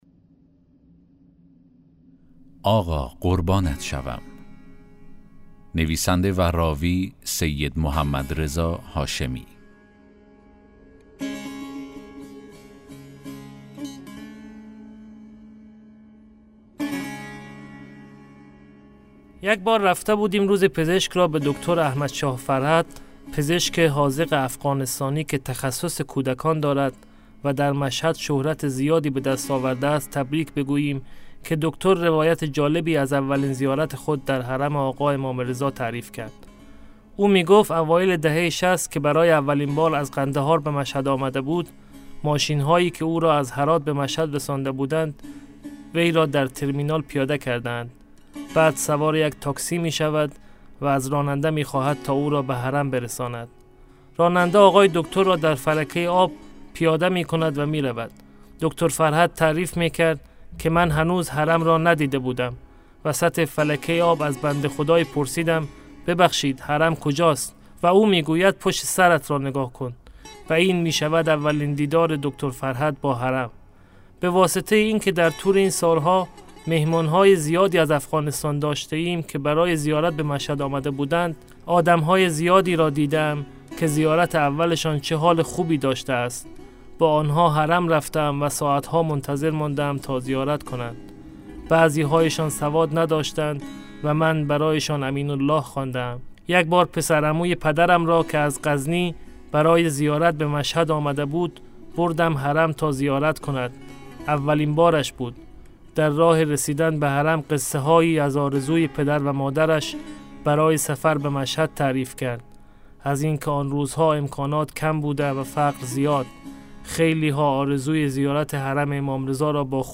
داستان صوتی: آقا قربانت شوم!